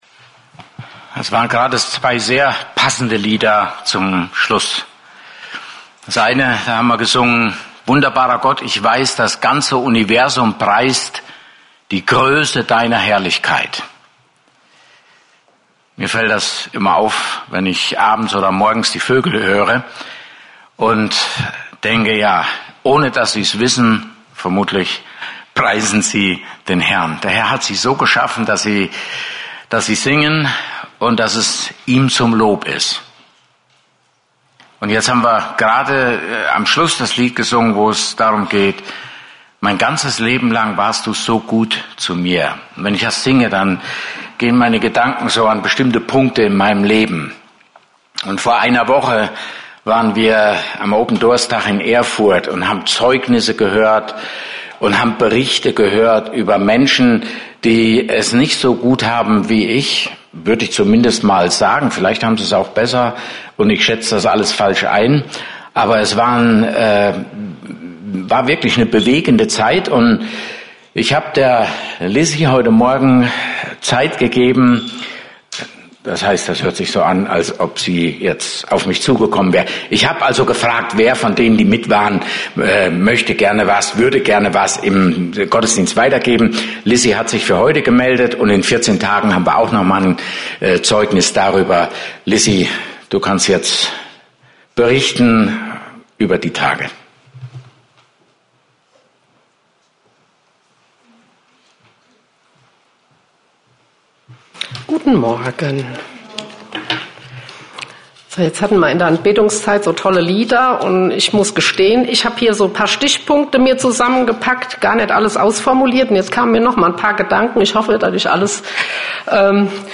Apostelgeschichte 1:8 Service Type: Gottesdienst Topics